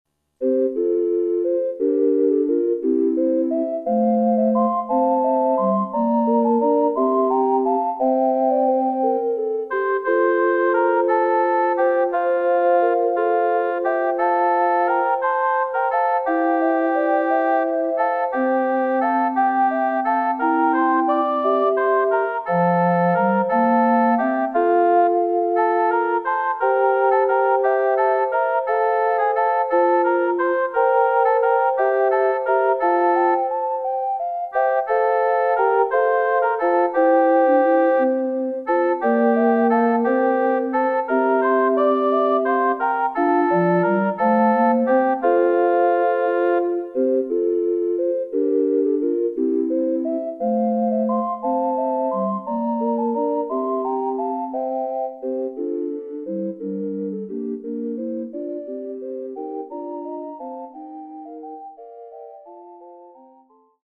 for Voice and Quartet
SiSATB